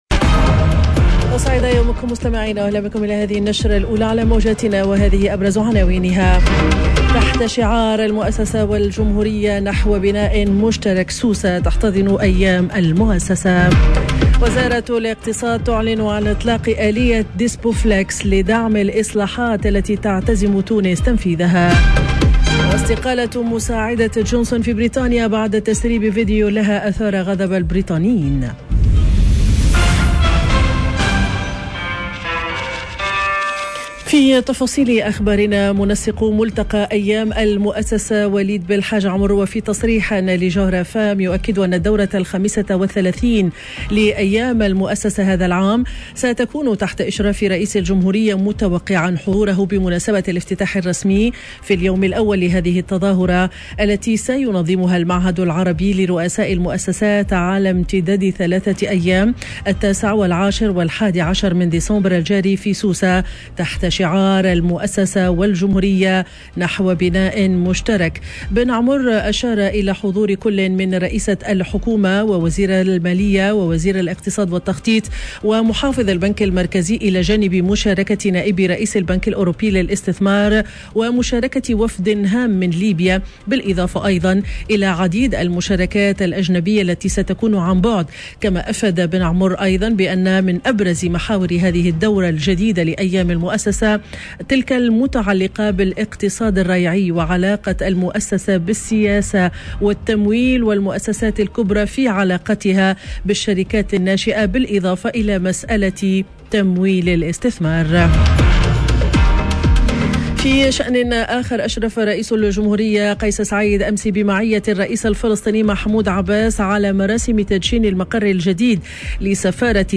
نشرة أخبار السابعة صباحا ليوم الخميس 09 ديسمبر 2021